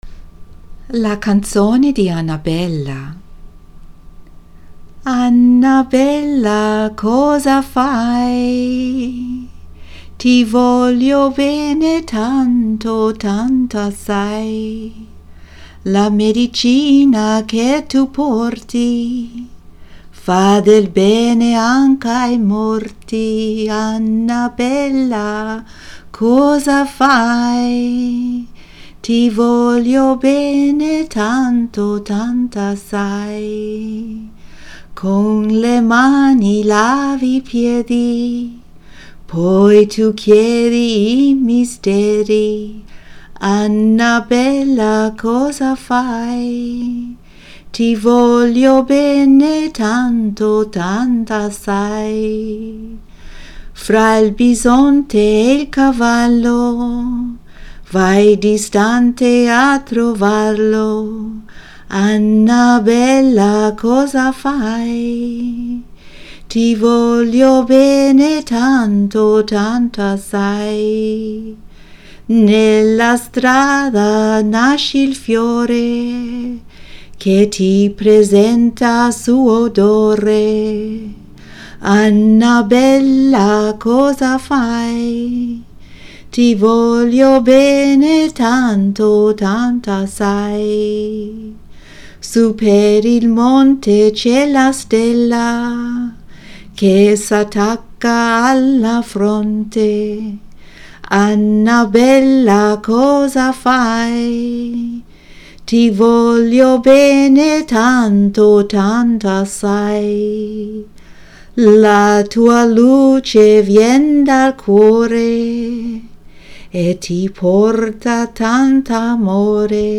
Chants Her Truth